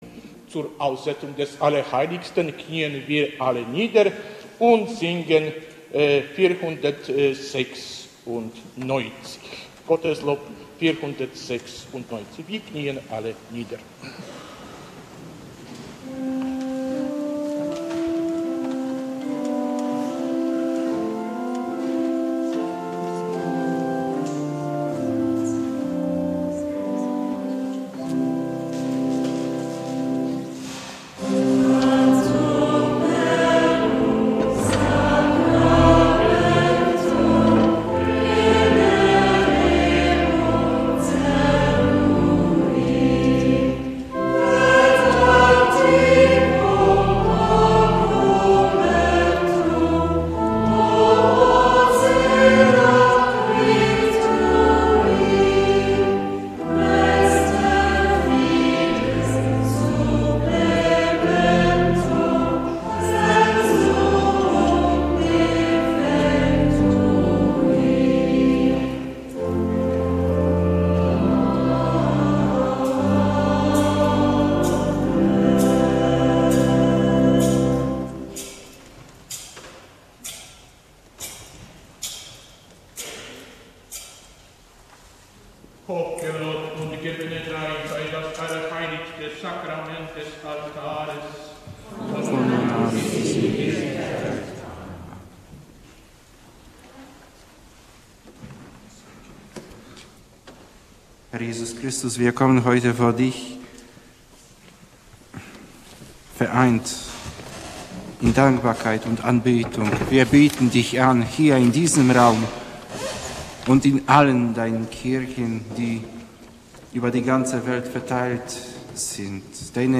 Die Übertragung von Radio Maria der Hl. Messe und der Eucharistischen Anbetung zum hören:
Eucharistische Anbetung, Betrachtungen und Lobpreis